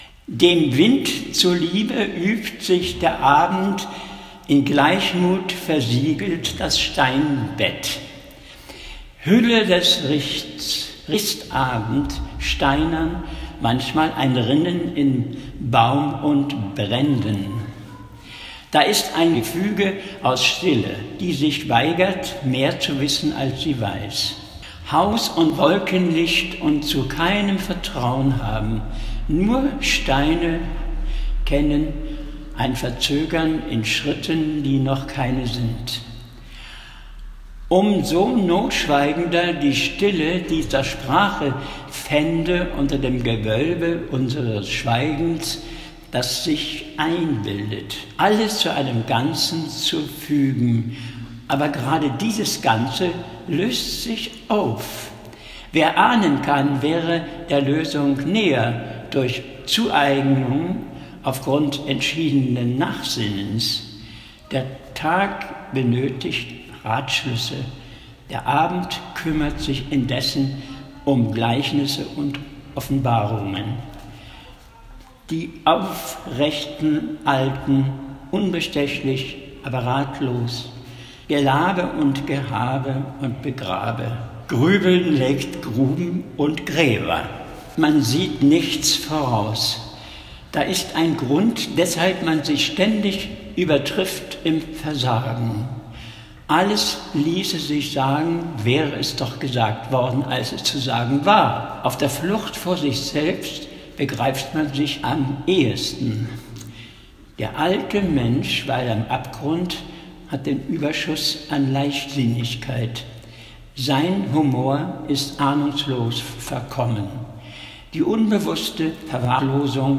Deine Sprache immer klar und schön.